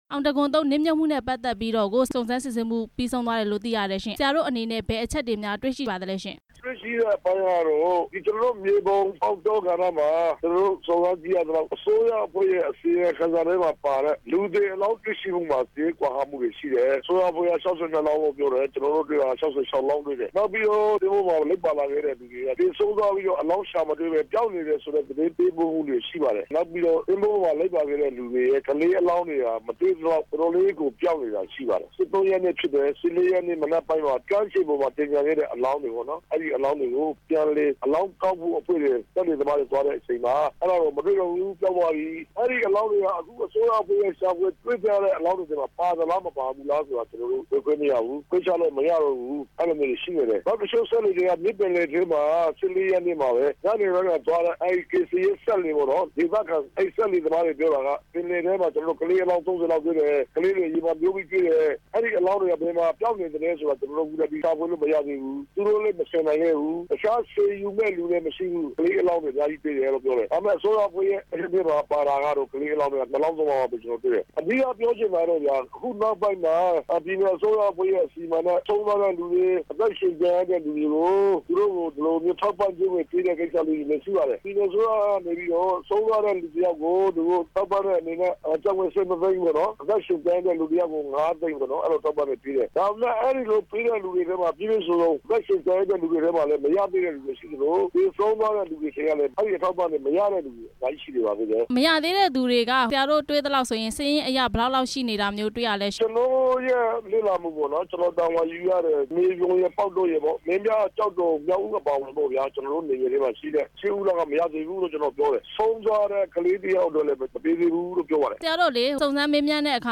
အောင်တံခွန် ရေယာဉ်နစ်မြုပ်မှု အစီရင်ခံစာအကြောင်း မေးမြန်းချက်